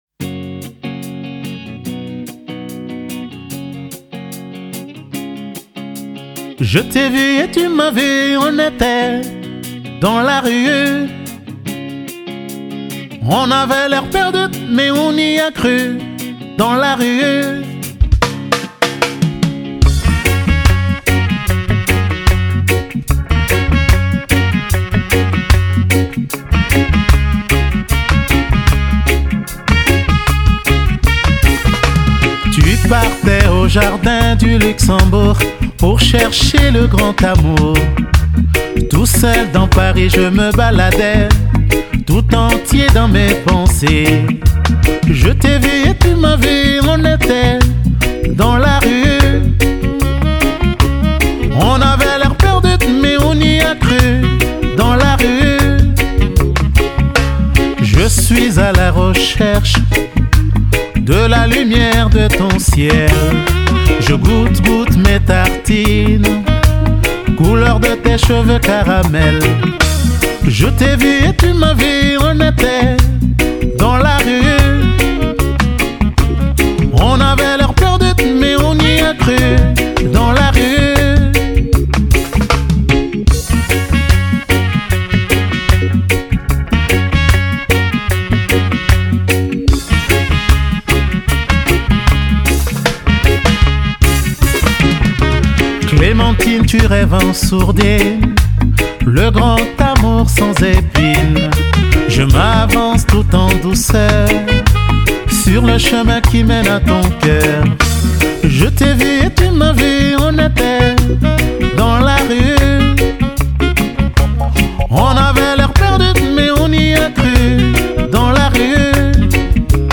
a été enregistré dans les conditions d’un live.